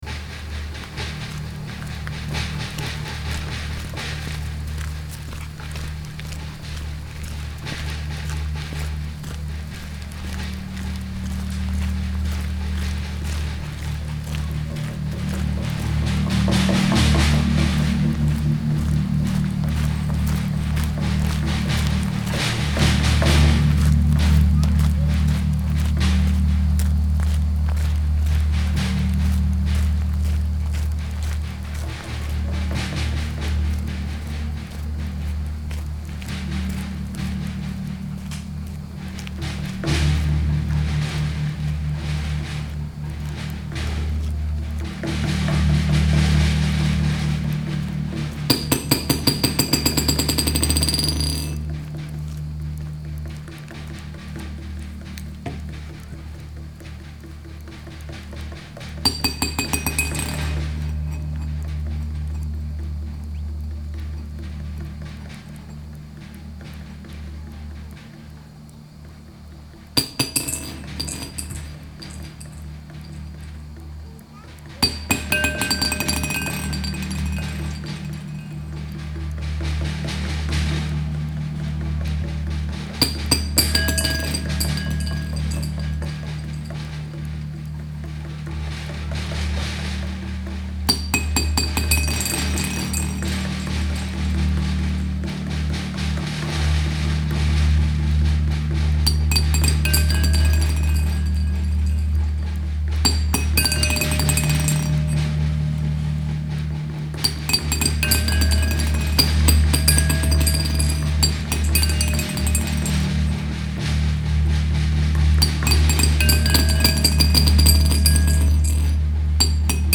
Radio Concrete is a monthly experimental radio show focusing on sounds we’re exposed to every day in the public and domestic spheres - using field recordings, amplified and toys and live sources such as am/fm radio.